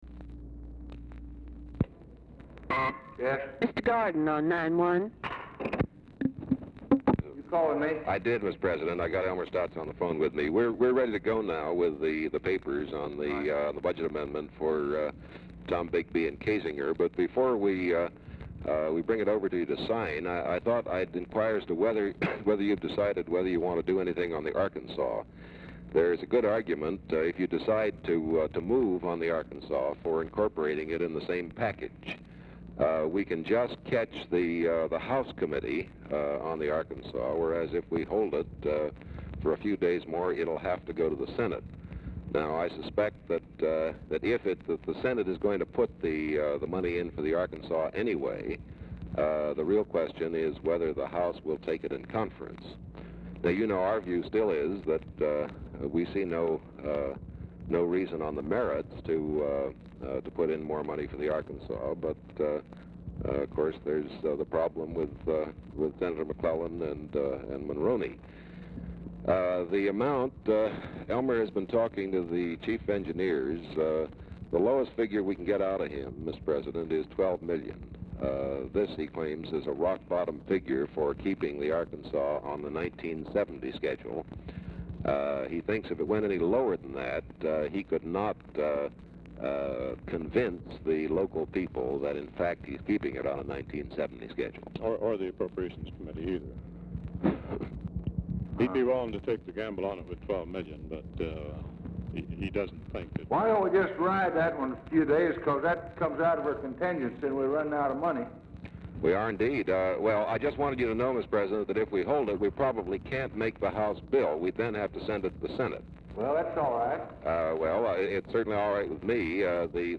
Telephone conversation # 3529, sound recording, LBJ and KERMIT GORDON, 5/27/1964, 3:40PM | Discover LBJ
LBJ IS ON SPEAKERPHONE
Format Dictation belt
Oval Office or unknown location